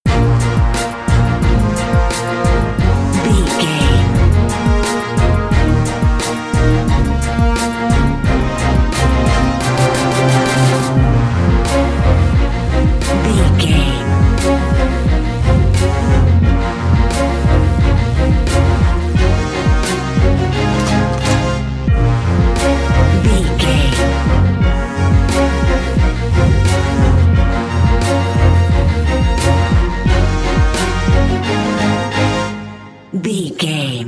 Fast paced
In-crescendo
Aeolian/Minor
B♭
strings
drums
horns
funky